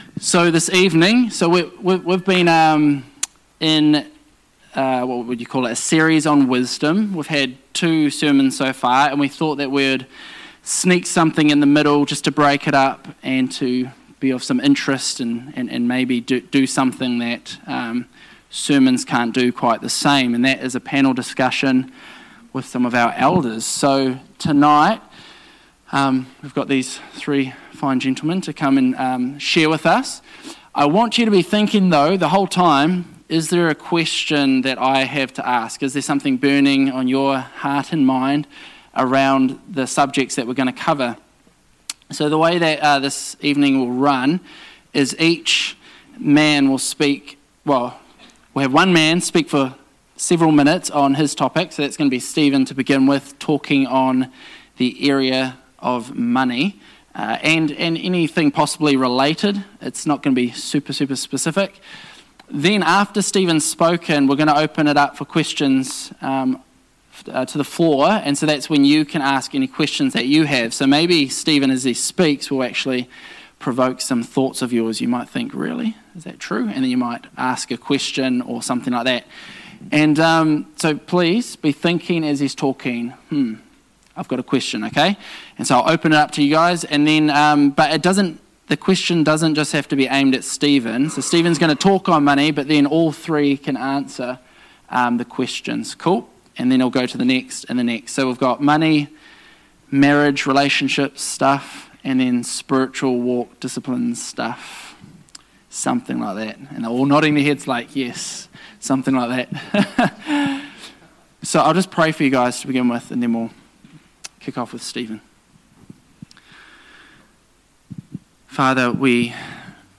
Wisdom – Panel Discussion 24 May 2019